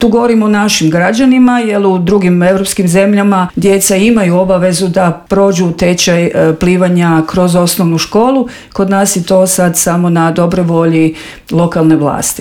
audio intervjuu